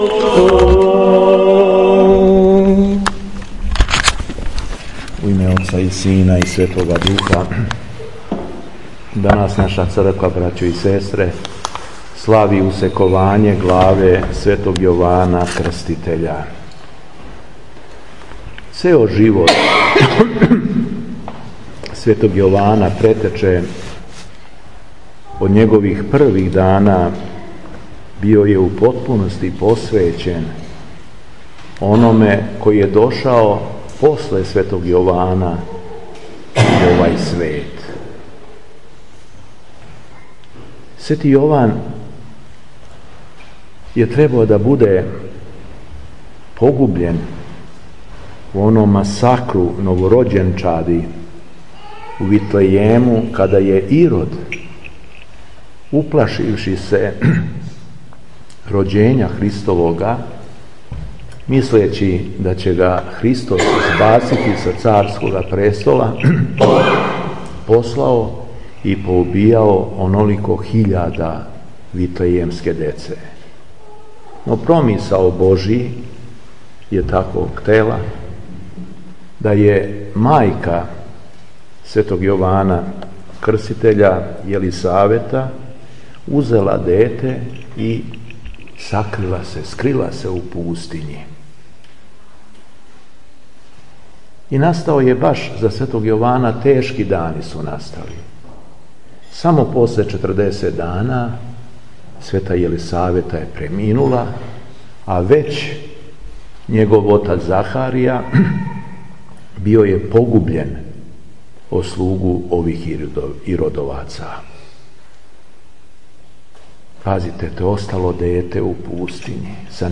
СВЕТА АРХИЈЕРЕЈСКА ЛИТУРГИЈА У МАНАСТИРУ КАЛЕНИЋ - Епархија Шумадијска
Беседа Епископа шумадијског Г. Јована